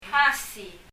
パラオ語には、いわゆる ｓｈ [ʃ] の音がないようで、「hasi」も [hʌʃi] ではなくて、[hʌsi]と聞こえます。